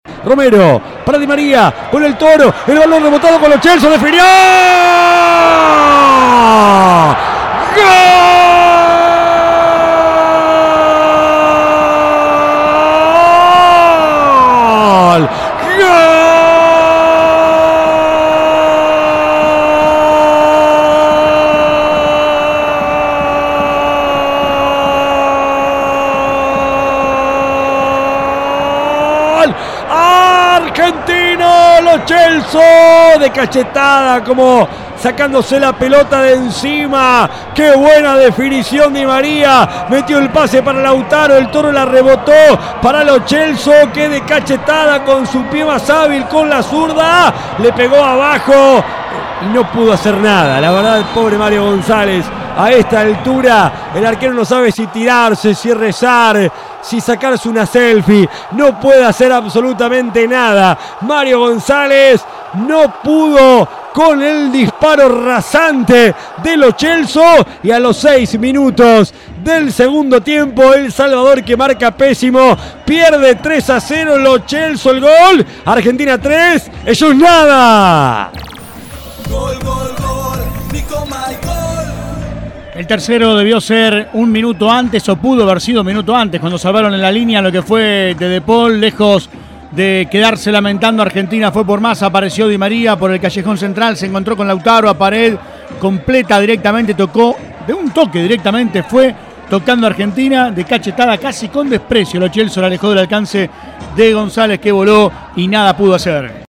Volvé a vibrar con el relato de los tres goles argentinos ante El Salvador